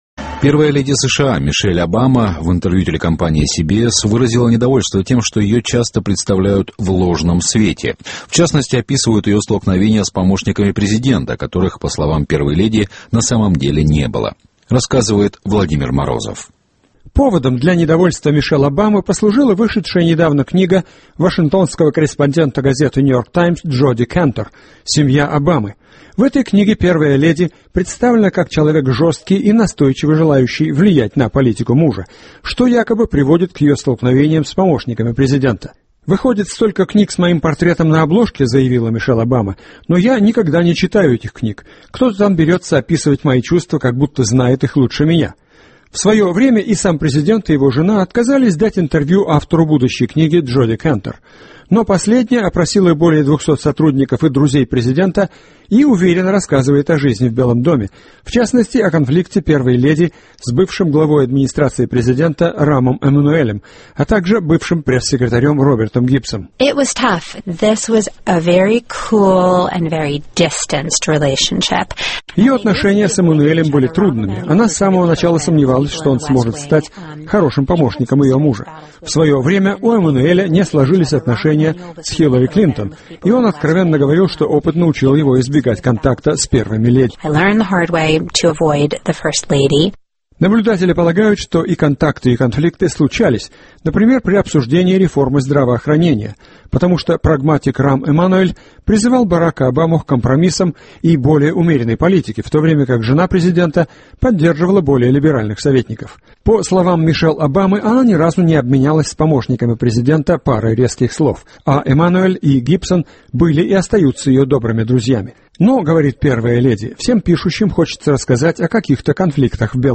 Первая леди США Мишель Обама в интервью телекомпании CBS выразила недовольство тем, что ее часто представляют в ложном свете.